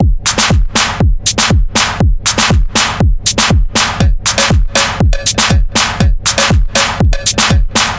牛铃拨浪鼓 1
描述：牛铃响的样本。 用Zoom H4n板载立体声话筒录制。
标签： 卡通 戒指 牛铃 缩放 H4n 摇铃 贝尔 SFX 弗利
声道立体声